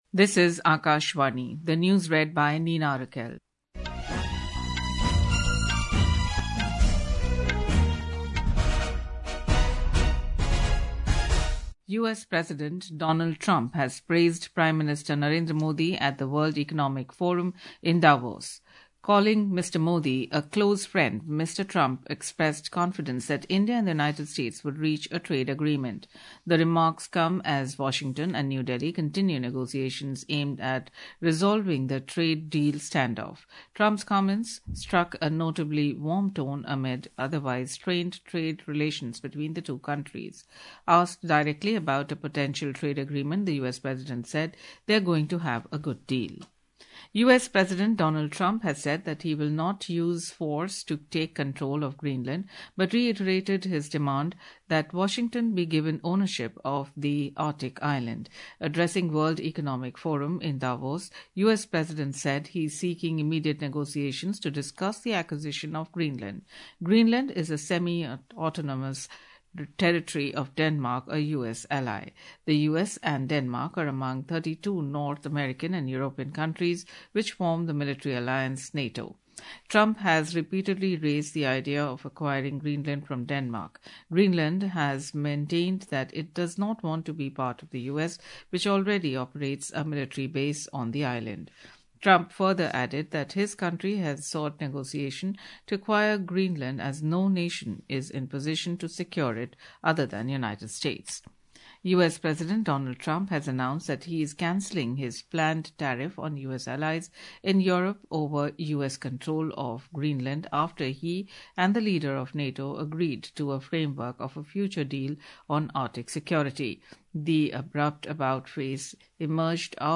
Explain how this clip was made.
Morning News 07 Jan 2026 | 8.15 AM This is a pre-recorded audio bulletin titled "Midday News" in the Midday News category, published on 07 Jan 2026 at 2.00 PM.